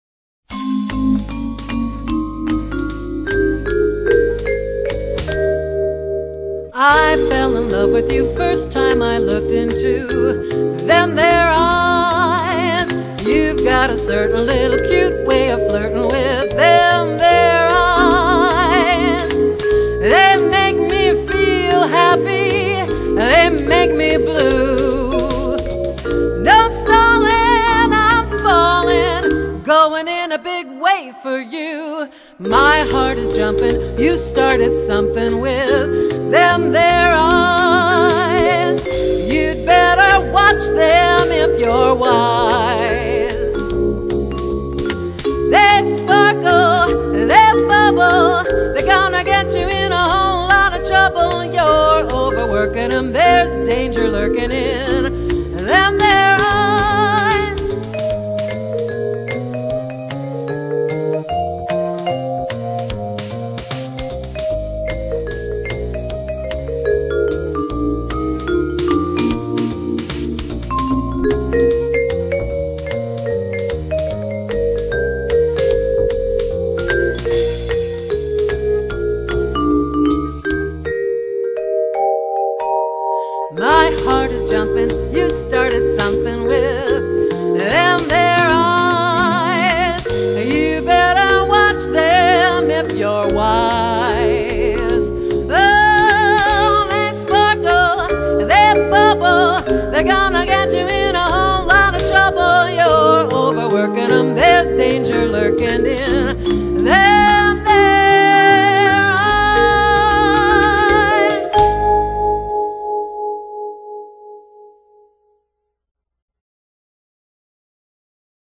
Solo performances use pre-programmed accompaniment tracks.
This makes the music sound full, classy & fun.